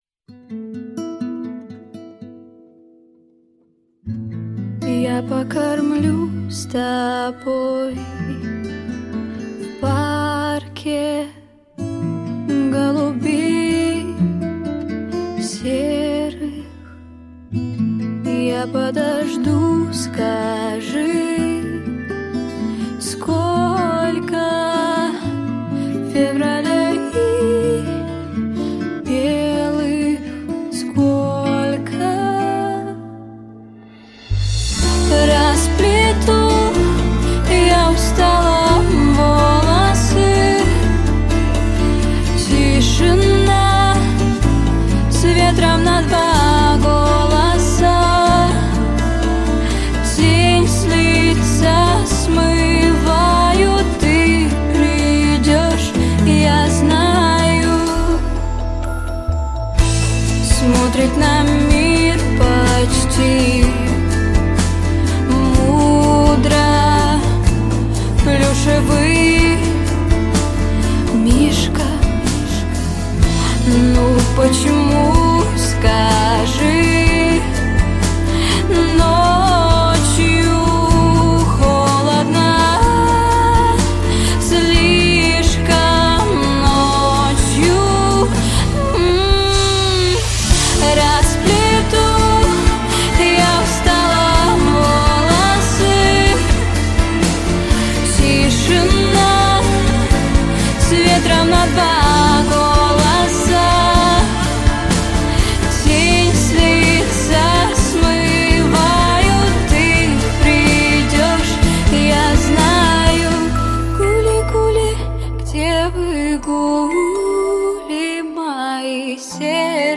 • Категория: Детские песни
Детская эстрадная вокальная студия.